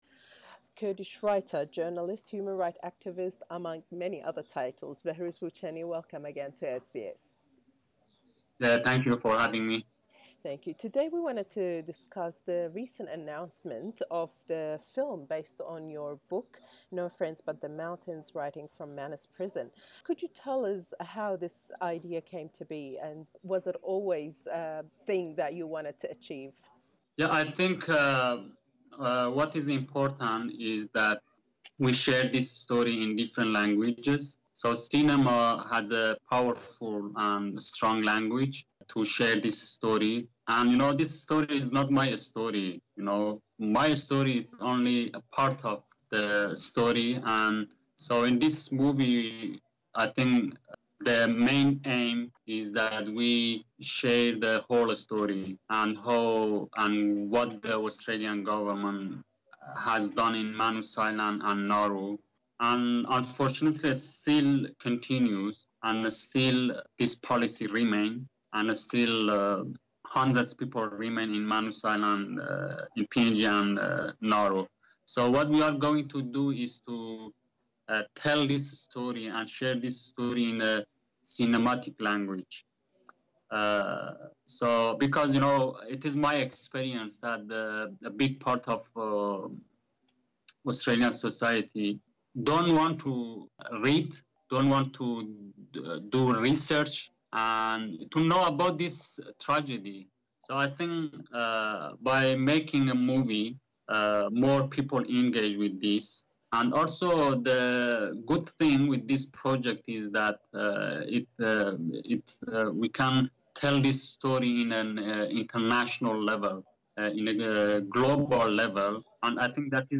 Behrouz Boochani speaking with SBS Kurdish Source: SBS Kurdish